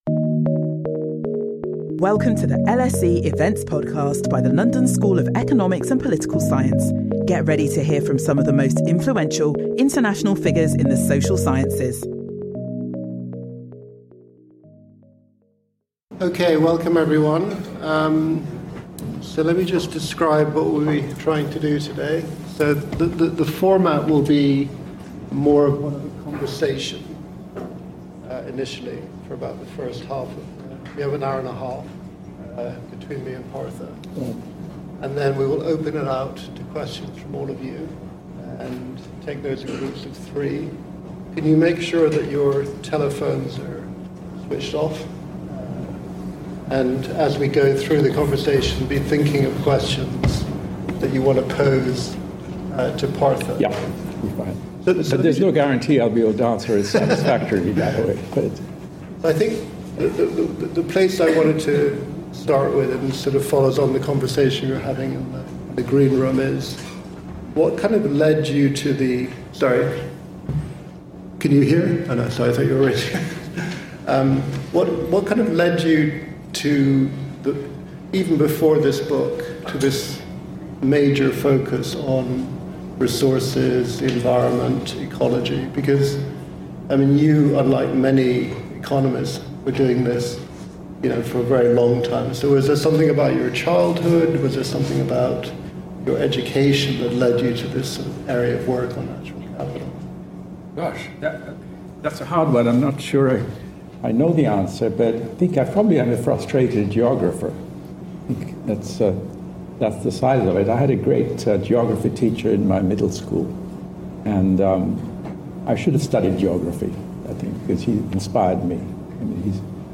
Join us for a conversation with Partha Dasgupta, Emeritus Professor of Economics at the University of Cambridge, as he discusses his latest book On Natural Capital where he lays out a seminal and groundbreaking new approach to economics. Challenging everything that has come before, he asks, what if we were to put a value on nature just as we value everything else?